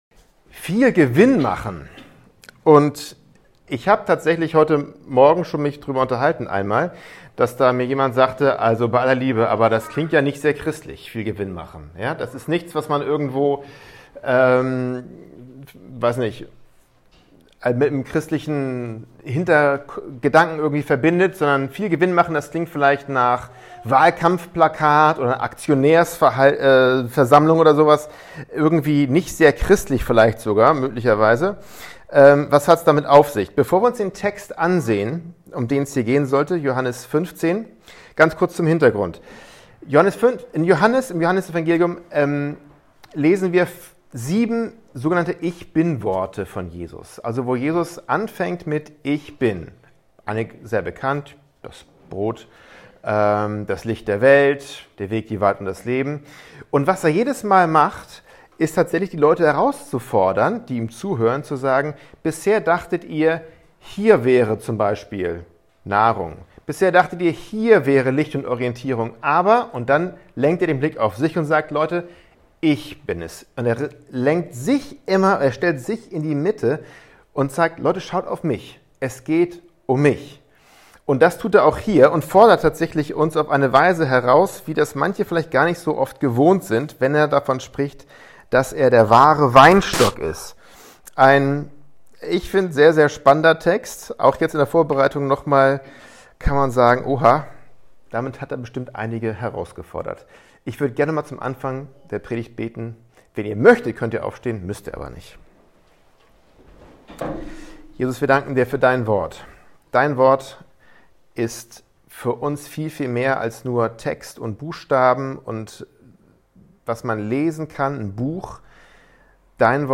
Standortgottesdienste Gewinn oder Rendite werden heute regelmäßig versprochen